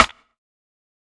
TC2 Snare 24.wav